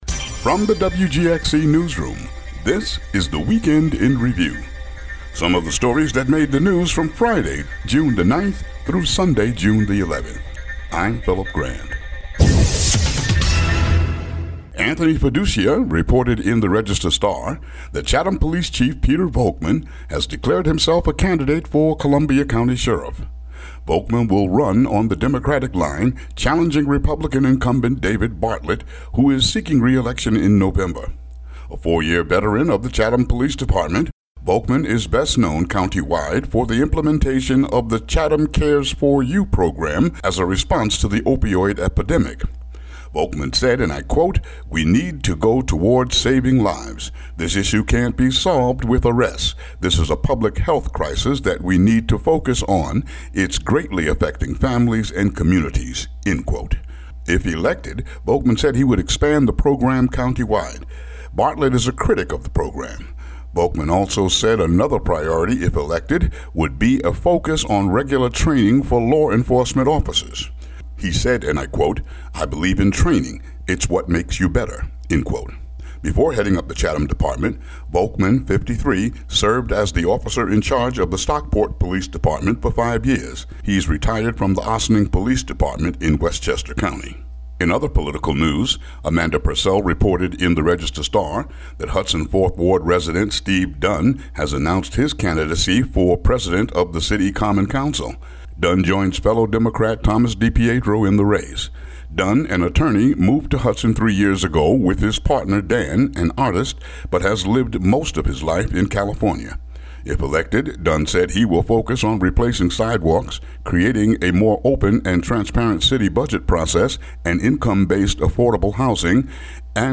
WGXC daily headlines for June 12, 2017.